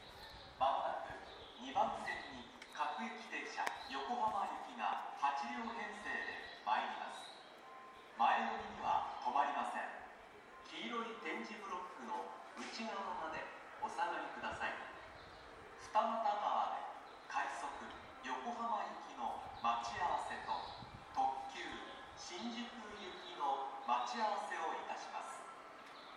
２番線SO：相鉄線
接近放送各駅停車　横浜行き接近放送です。